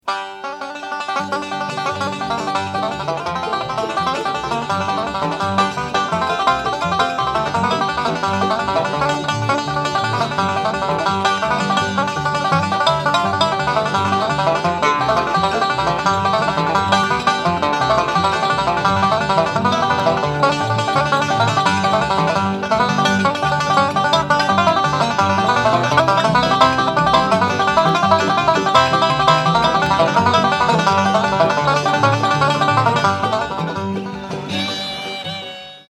Bluegrass
Recorded on October 31, 1964 in Jordan Hall, Boston.